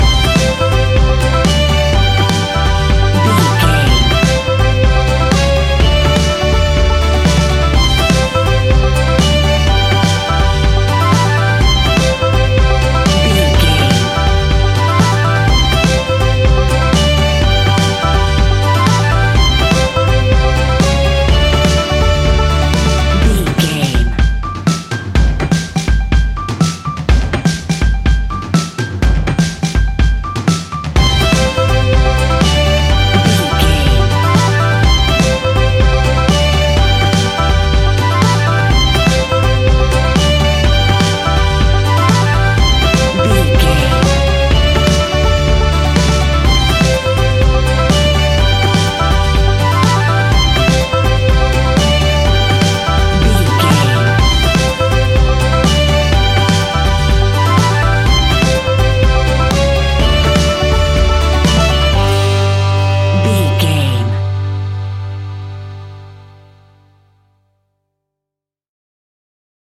Uplifting
Ionian/Major
Fast
folk instrumentals
acoustic guitar
mandolin
ukulele
lapsteel
drums
double bass
accordion